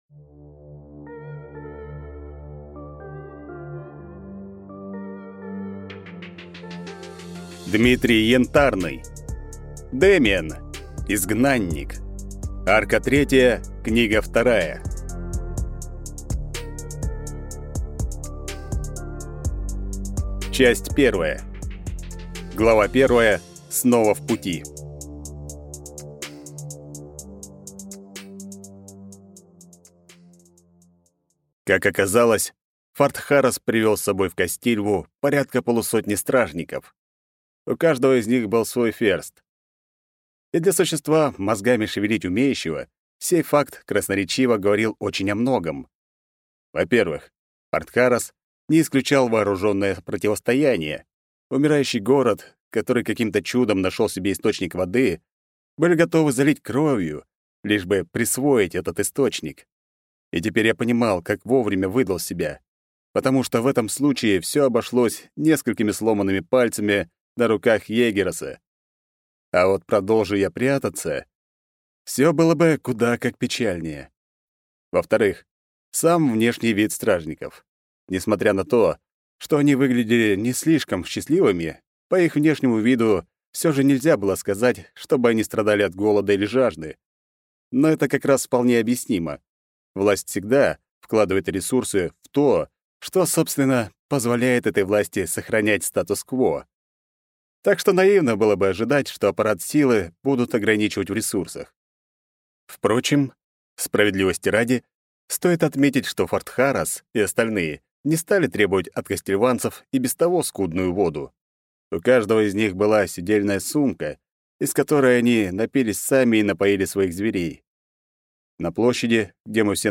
Аудиокнига Изгнанник. Арка 3. Книга 2 | Библиотека аудиокниг